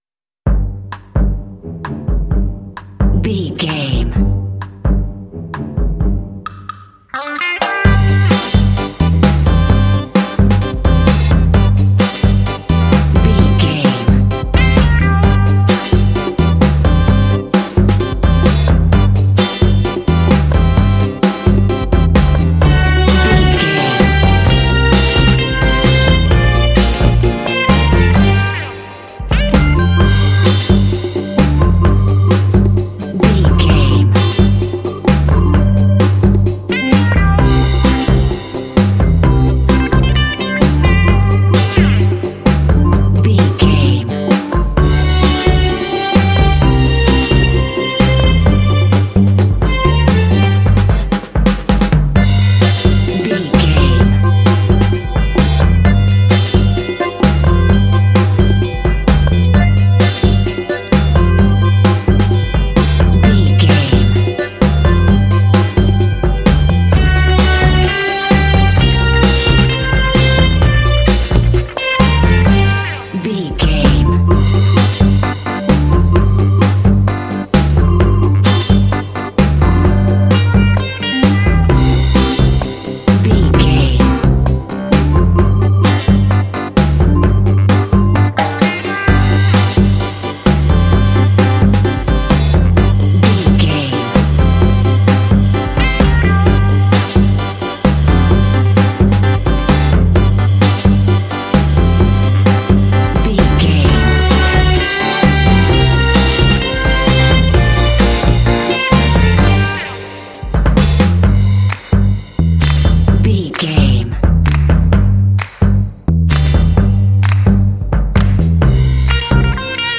Uplifting
Aeolian/Minor
funky
smooth
groovy
driving
happy
bright
drums
brass
electric guitar
bass guitar
organ
conga
Funk